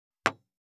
202,机に物を置く,テーブル等に物を置く,食器,グラス,コップ,工具,小物,雑貨,コトン,トン,ゴト,ポン,ガシャン,ドスン,ストン,カチ,タン,バタン,スッ,サッ,コン,
コップ効果音物を置く